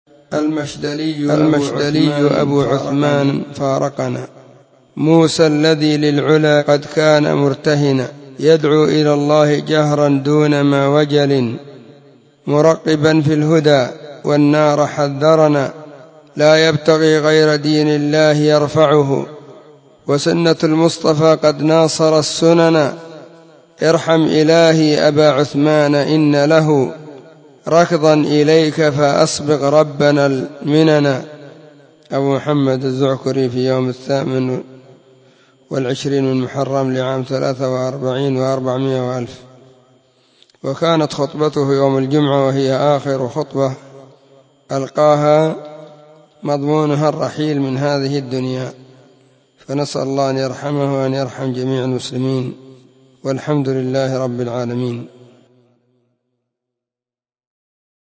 📢 مسجد الصحابة بالغيضة, المهرة، اليمن حرسها الله.
الأحد 28 محرم 1443 هــــ | محاولات شعرية | شارك بتعليقك